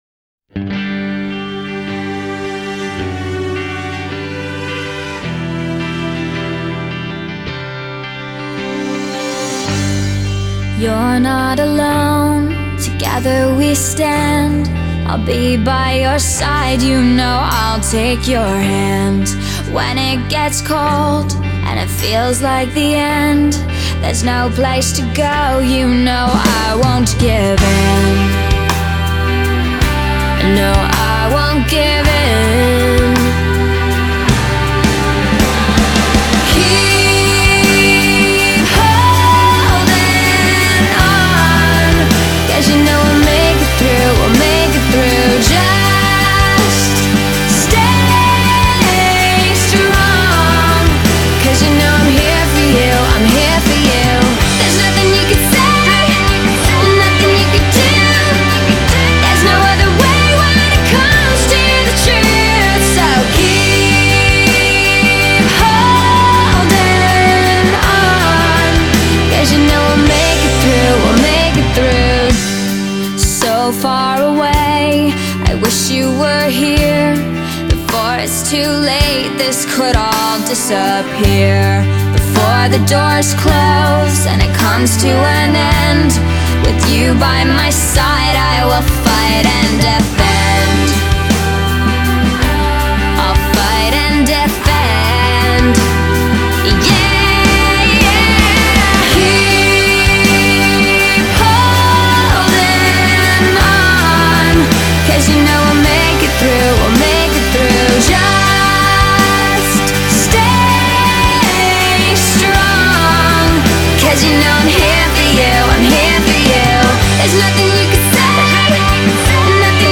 • Жанр: Alternative